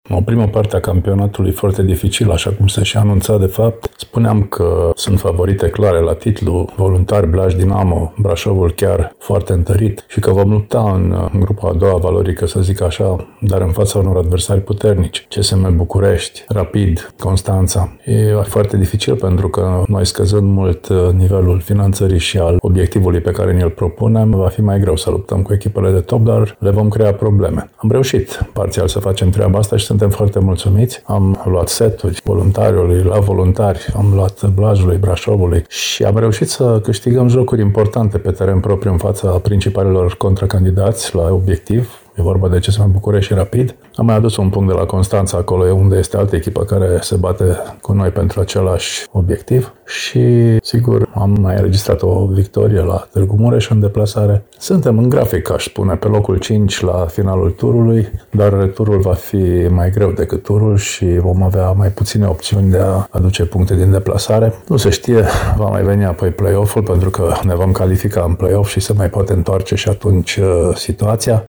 Într-un interviu pentru Radio Reşiţa, oficialul grupării timişene este mulţumit şi pentru faptul că echipa din Lugoj este, din nou, angrenată în cupele europene şi vorbeşte şi despre alt plus din acest sezon: folosirea a nouă jucătoare autohtone pe aproape toată durată stagiunii: